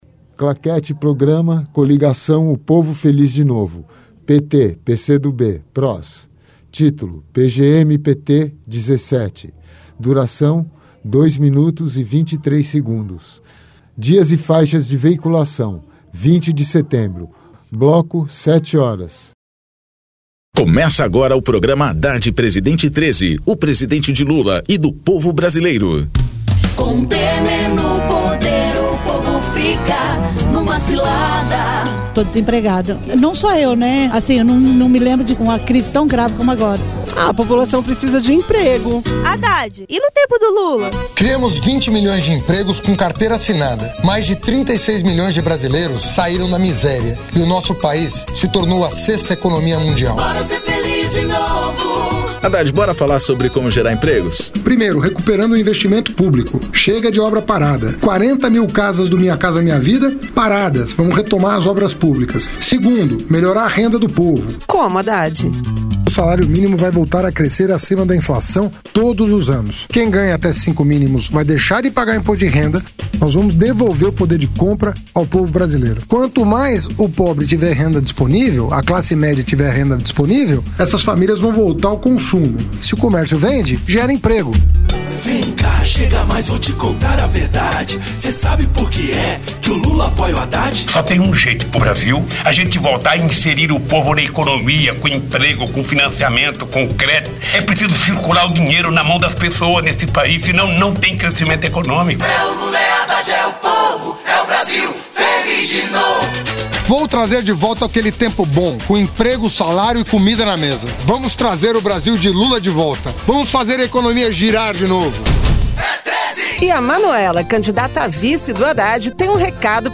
Gênero documentaldocumento sonoro
Descrição Programa de rádio da campanha de 2018 (edição 17) - 1° turno